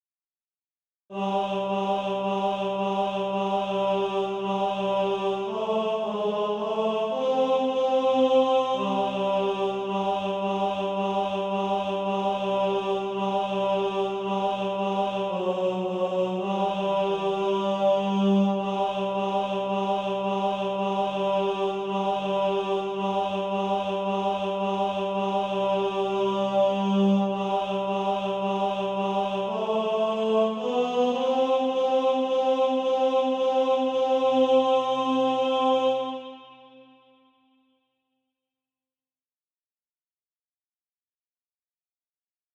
Tenor Track.
Practice then with the Chord quietly in the background.